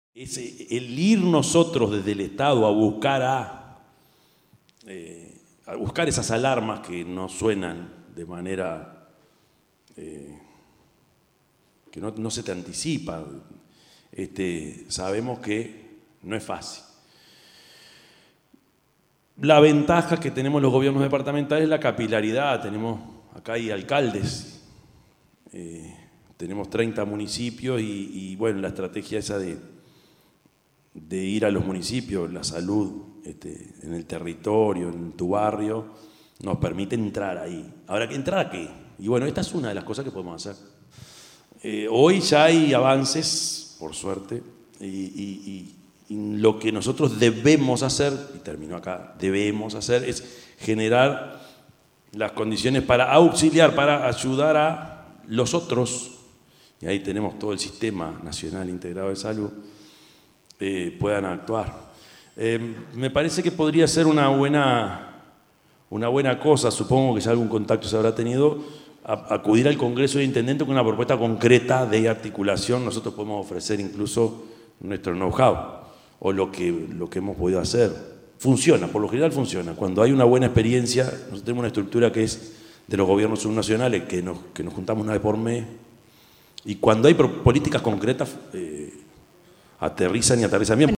yamandu_orsi_-_intendente_de_canelones_0.mp3